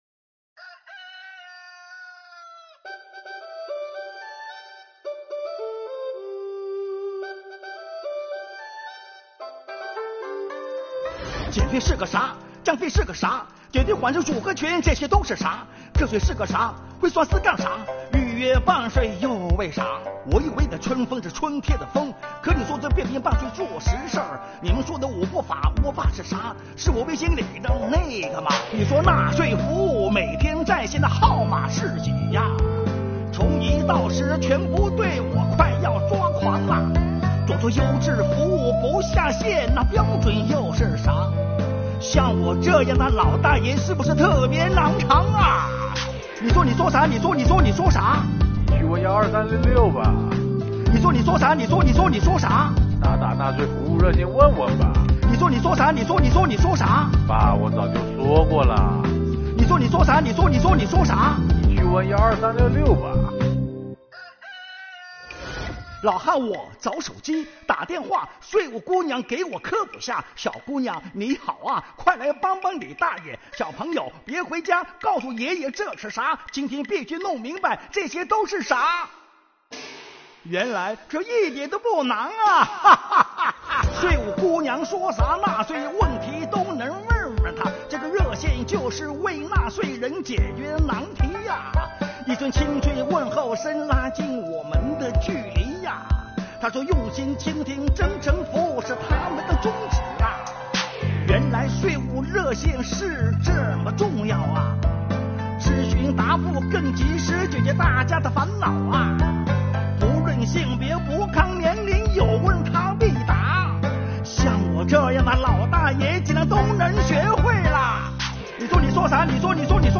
作品以父亲的视角讲述12366真诚为纳税人解决疑惑的故事，同时，在结尾穿插父爱的自然流露与细腻的心理活动，让受众的目光聚焦亲情。作品在结构上紧凑，节奏明快，取材于生活。